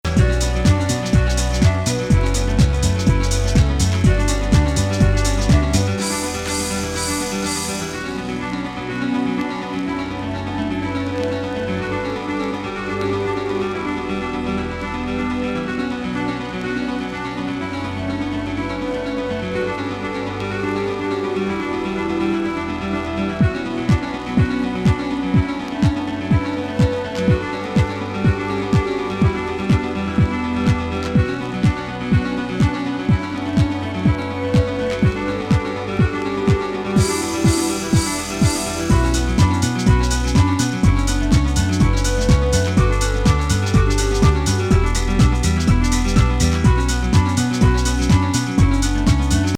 大仰なシンセ使いとクラシック的ミニマル・ピアノリフがいかにもなカルト・イタロ